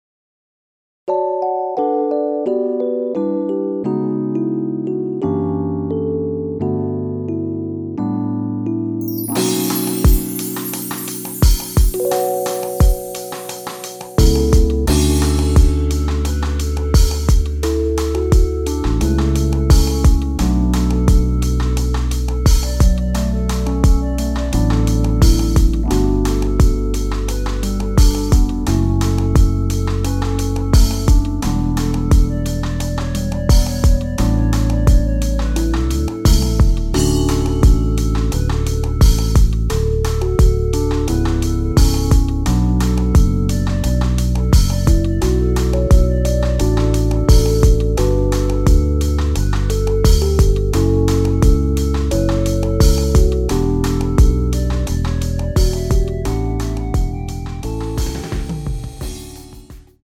엔딩이 페이드 아웃이라 노래 끝나고 바로 끝나게 엔딩을 만들어 놓았습니다.(원키 멜로디 MR 미리듣기 확인)
원키에서(-2)내린 멜로디 포함된 MR입니다.
앞부분30초, 뒷부분30초씩 편집해서 올려 드리고 있습니다.
중간에 음이 끈어지고 다시 나오는 이유는